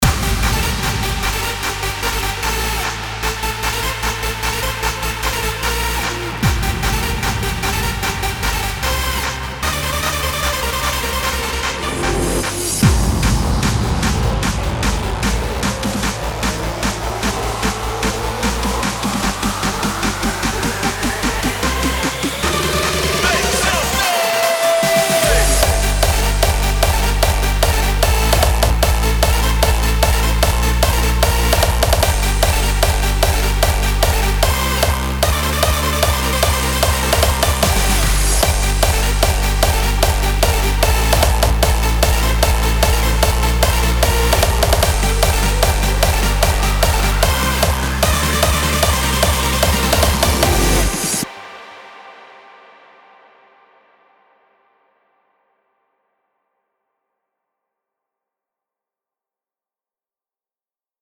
5天前 DJ音乐工程 · Hardstyie风格 2 推广